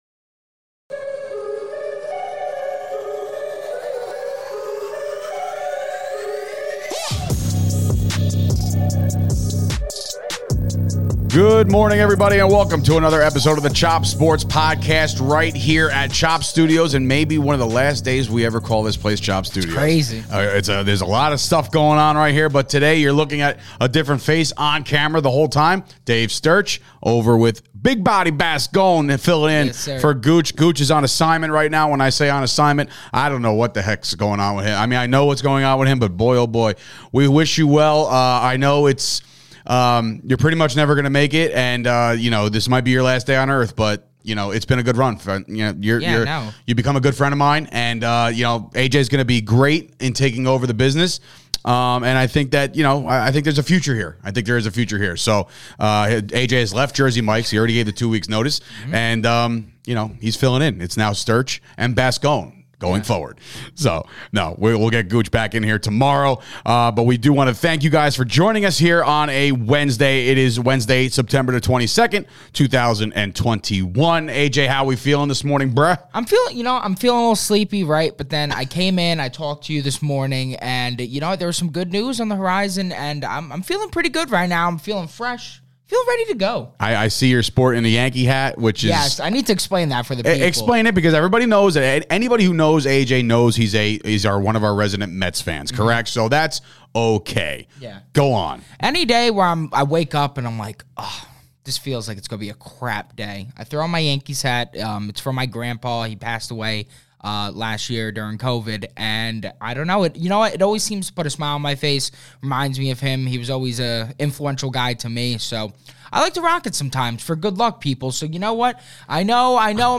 in studio today to deliver the Hump Day edition of the Chop Sports Podcast as we are talking all things MLB Wild Card Race!